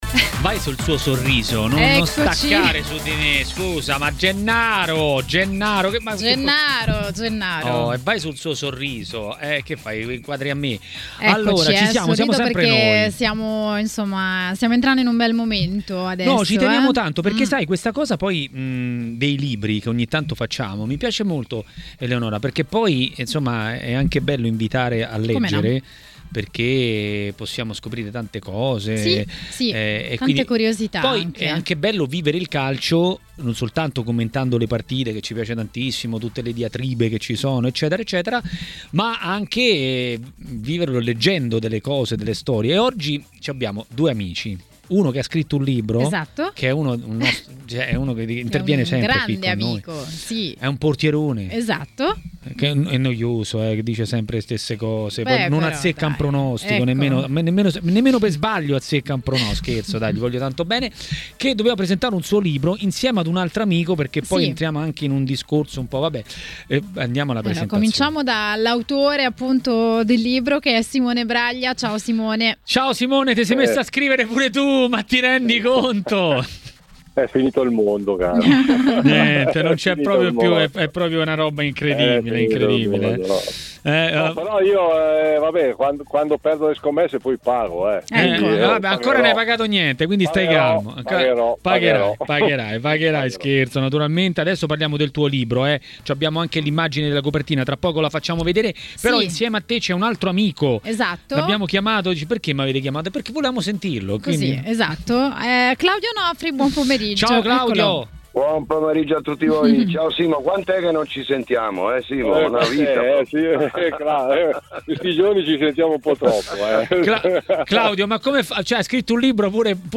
Ospiti: Simone Braglia